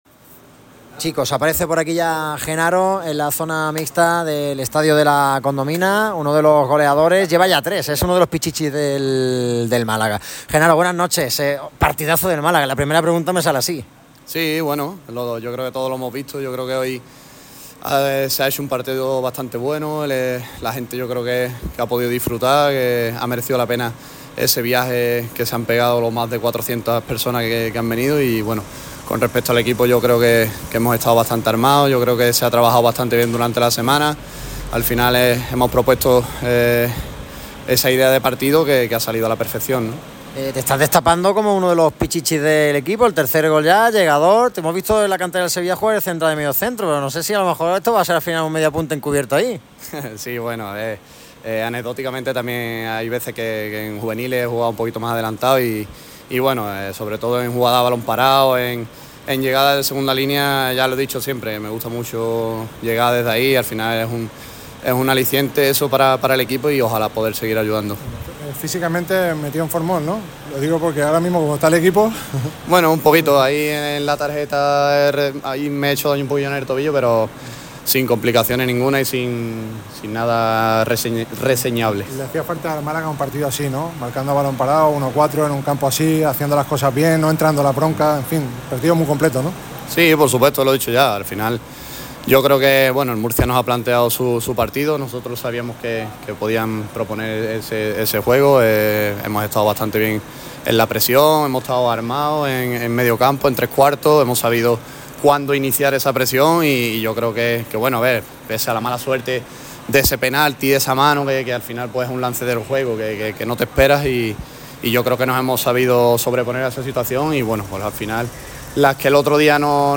Al término del partido en Murcia habló Genaro Rodríguez. El capitán malaguista valoró de forma muy positiva la victoria.